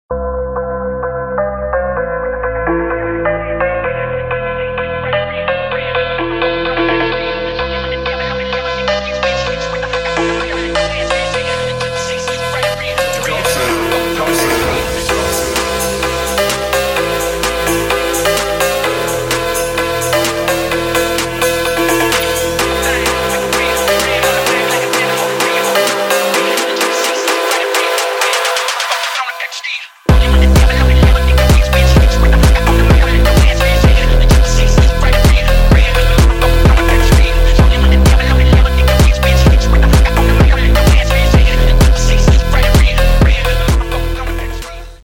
Phonk Remix Phonk